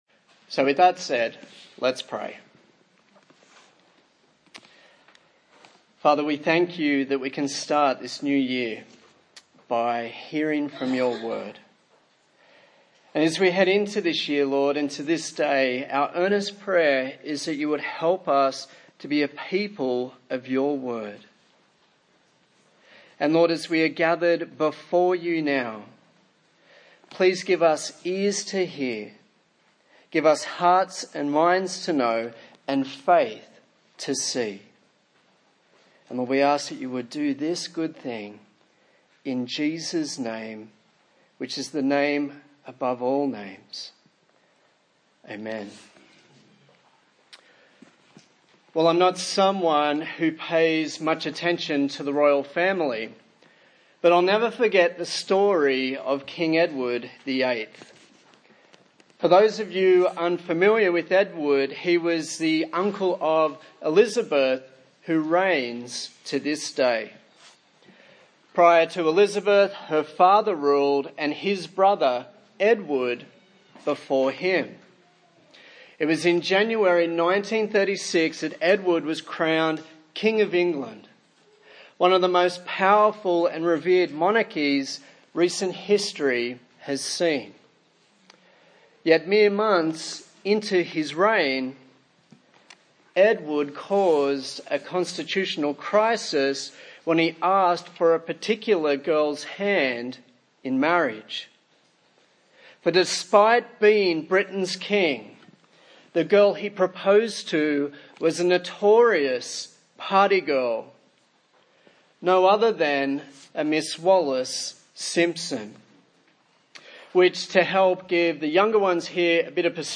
A sermon on the book of Philemon
Service Type: Sunday Morning